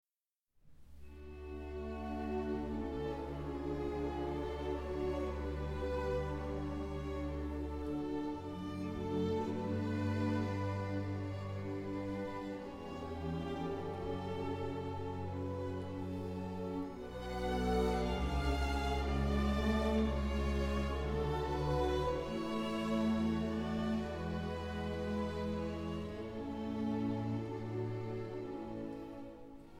Erbaulich, feierlich und ehrwürdig
für Streichorchester